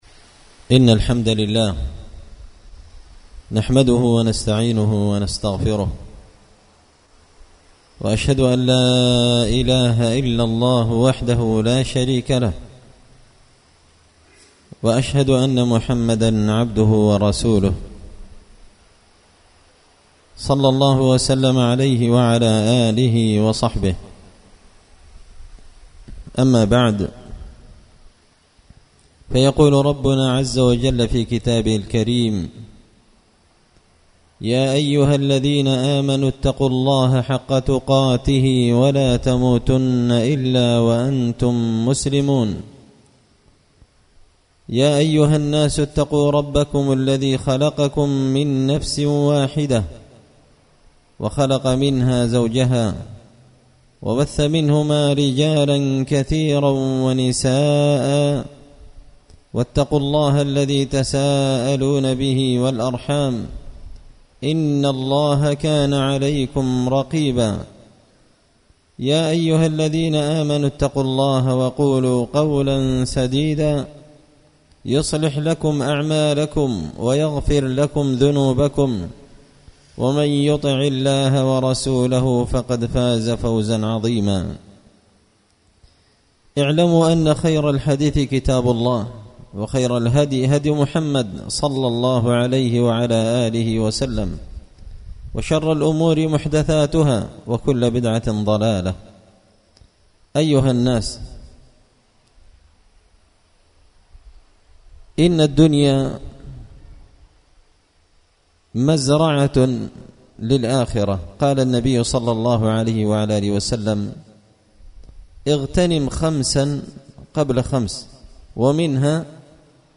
خطبة جمعة بعنوان – إغتنام الأوقات في العشر المباركات
دار الحديث بمسجد الفرقان ـ قشن ـ المهرة ـ اليمن